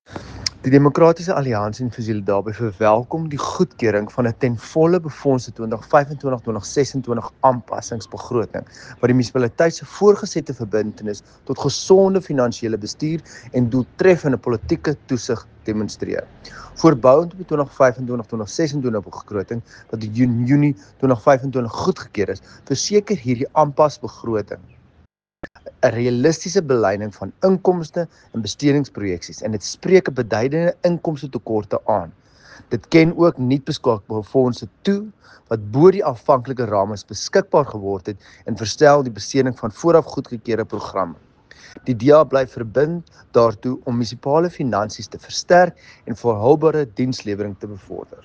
Afrikaans soundbite by Dr Igor Scheurkogel MP